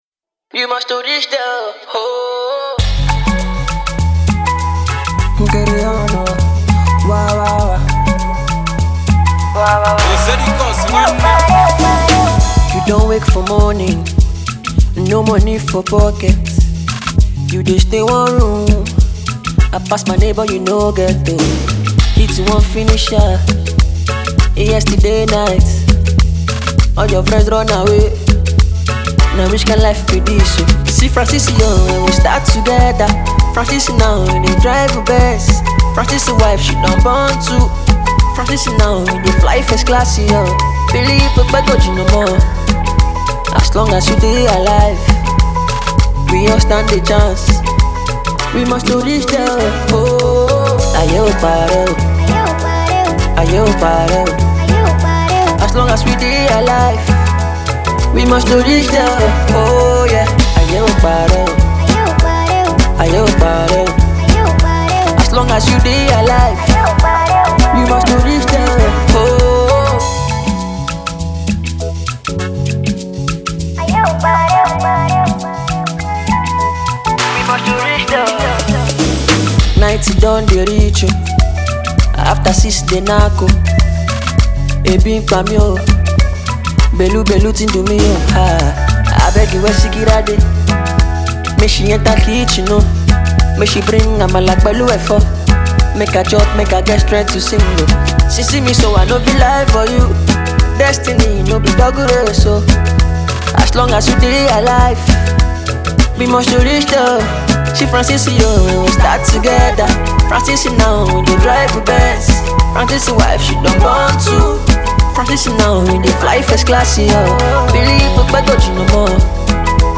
afrobeat tune
A lovely voice with amazing skills to deliver.